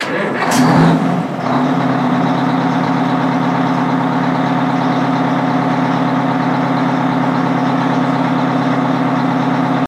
Highwayman Truck Start And Idle